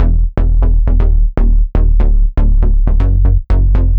Index of /musicradar/french-house-chillout-samples/120bpm/Instruments
FHC_NippaBass_120-E.wav